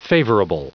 Prononciation du mot favorable en anglais (fichier audio)
Prononciation du mot : favorable